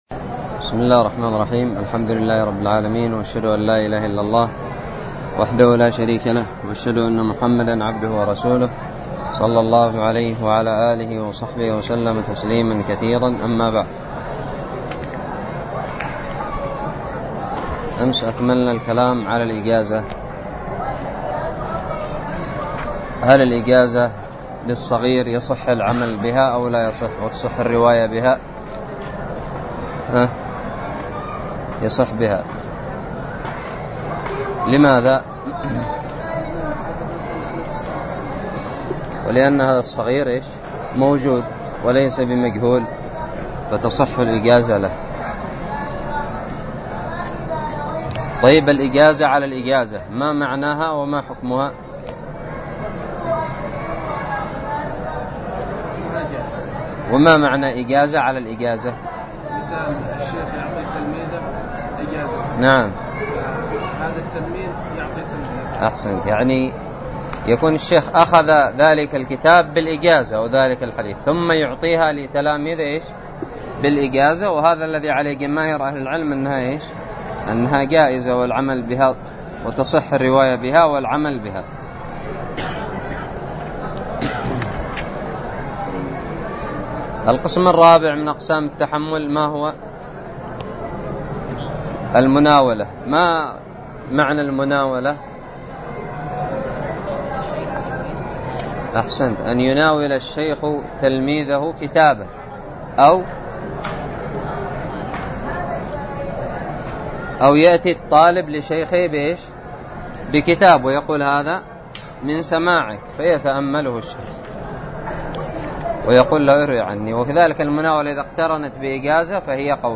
الدرس الخامس والثلاثون من شرح كتاب الباعث الحثيث
ألقيت بدار الحديث السلفية للعلوم الشرعية بالضالع